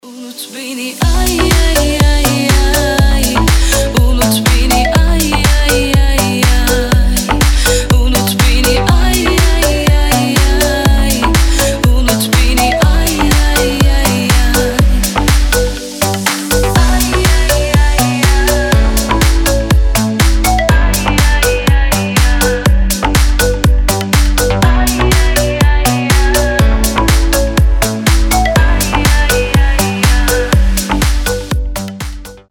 • Качество: 320, Stereo
deep house
лирические
Cover Mix